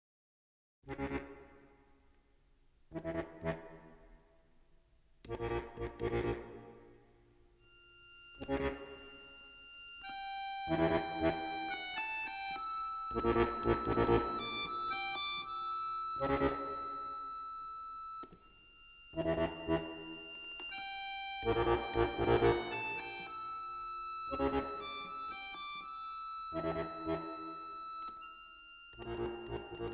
for bayan in 3 movements